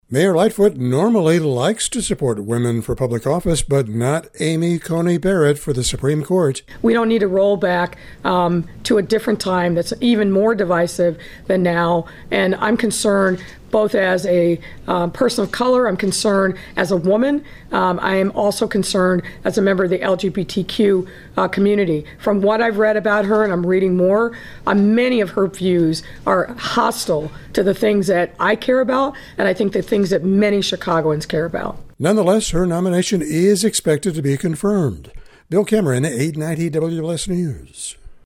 says so taking questions dressed up as the Coronavirus Destroyer at her presser to announce Halloween guidelines.